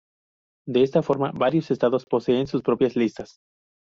Pronounced as (IPA) /ˈlistas/